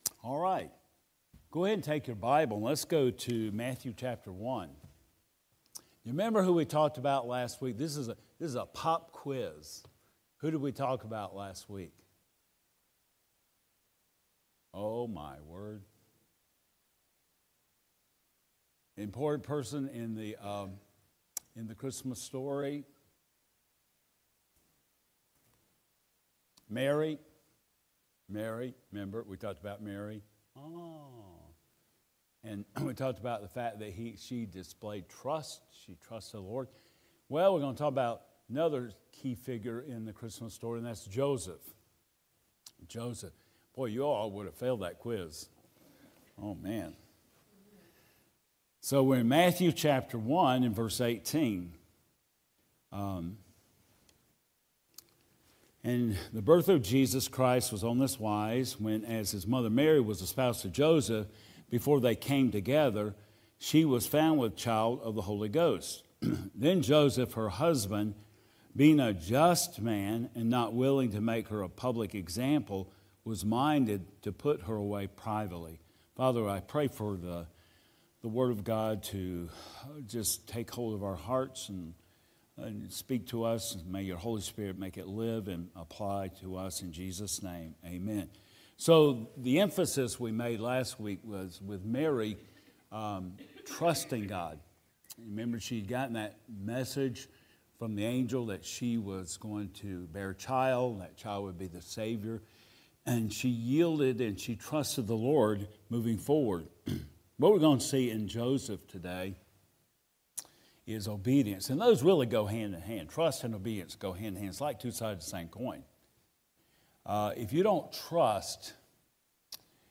Matt. 1:18 Service Type: Adult Sunday School Class « “Go in Peace”